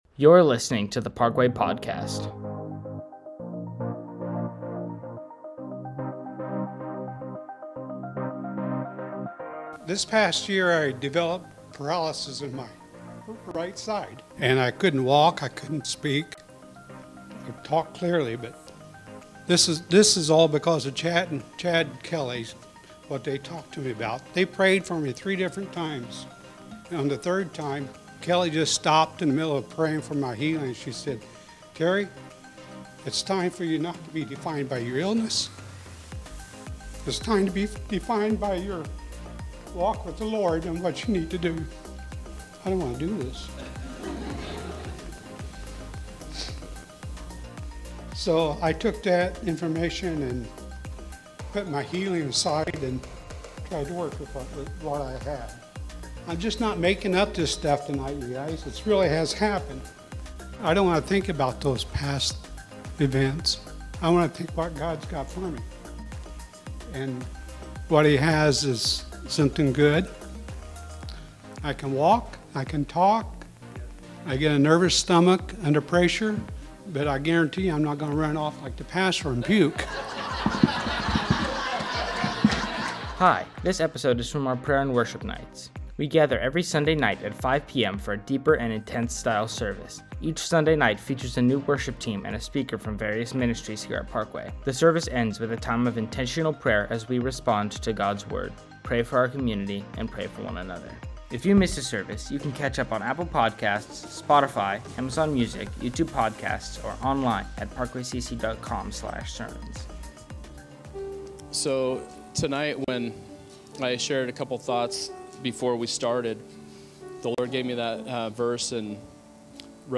From Series: "Prayer & Worship Nights"